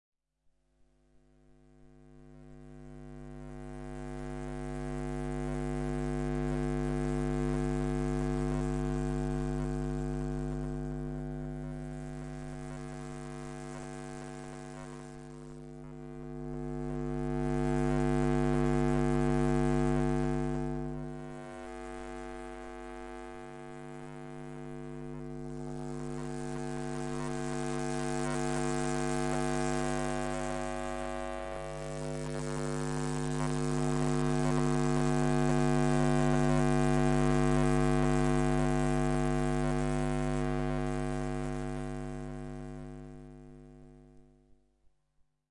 电磁场 " 地面主管
描述：使用Zoom H1和电磁拾音器录制